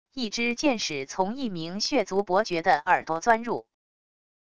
一支箭矢从一名血族伯爵的耳朵钻入wav音频